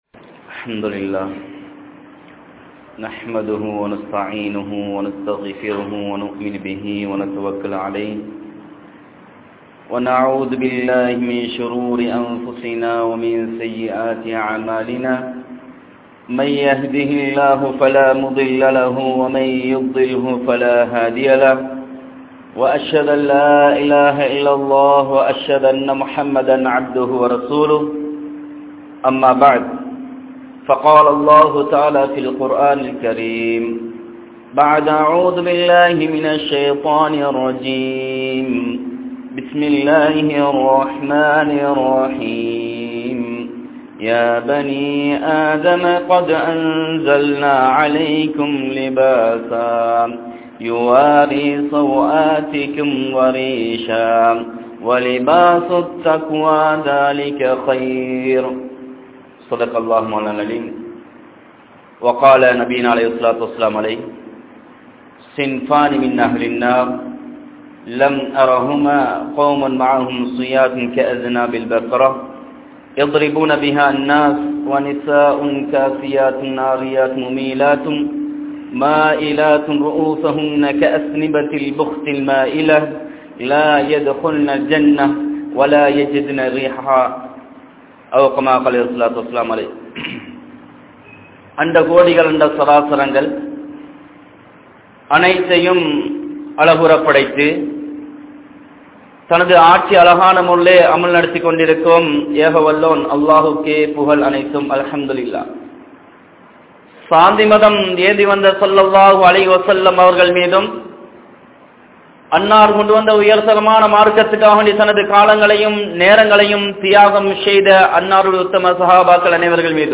Indraya Vaalifarhalin Aadaihal (இன்றைய வாலிபர்களின் ஆடைகள்) | Audio Bayans | All Ceylon Muslim Youth Community | Addalaichenai
Daulagala Jumua Masjidh